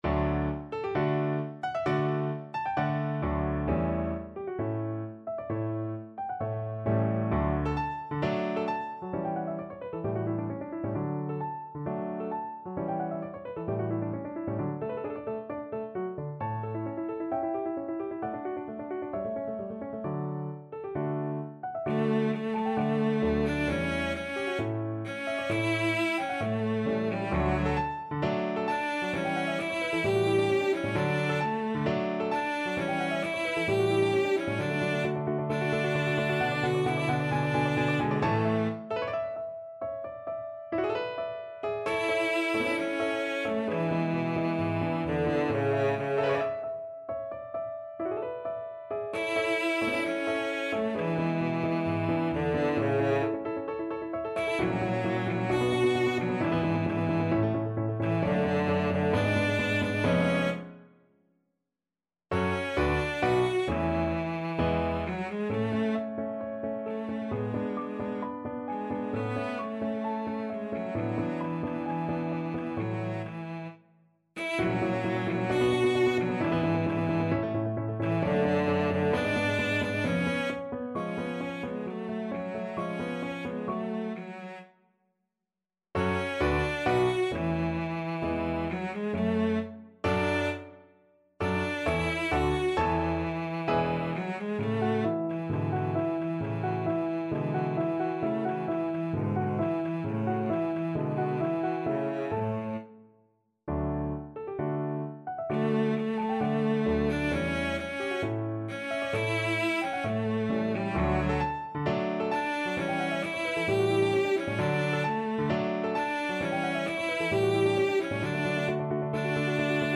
Cello
D major (Sounding Pitch) (View more D major Music for Cello )
4/4 (View more 4/4 Music)
~ = 132 Allegro (View more music marked Allegro)
Classical (View more Classical Cello Music)